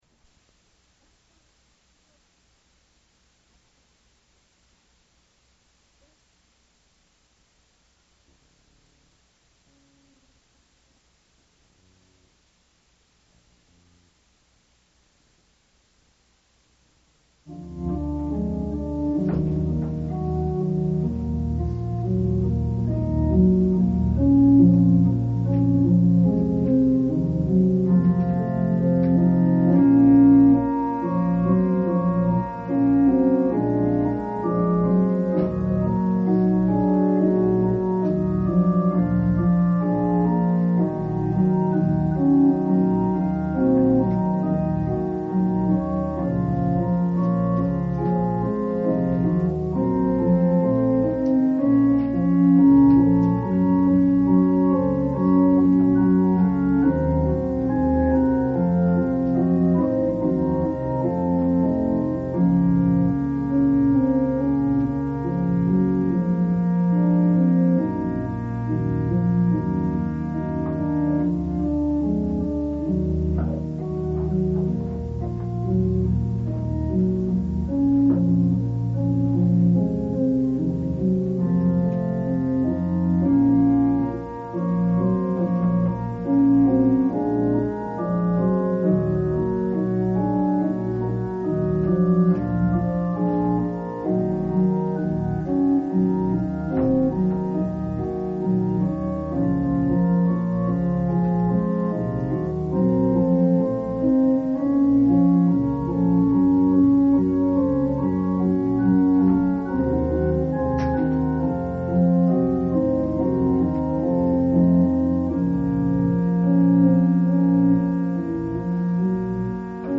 説教要旨 | 日本基督教団 世光教会 京都市伏見区